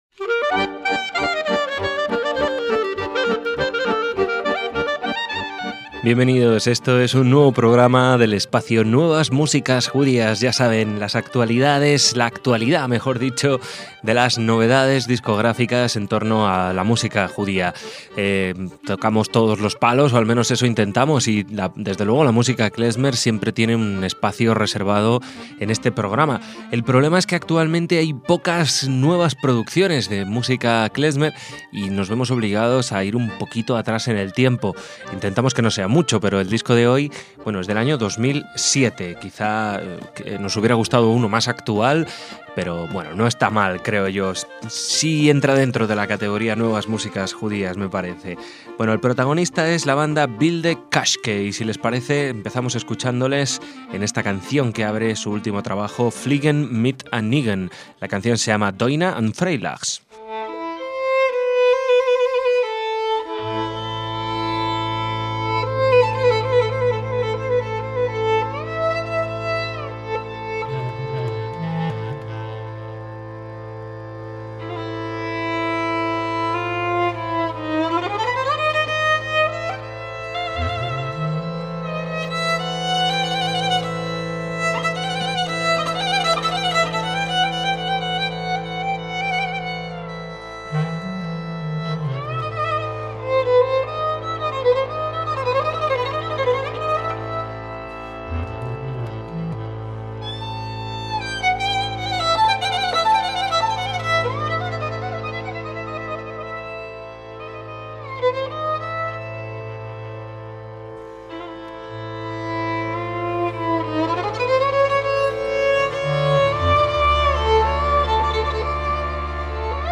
acordeón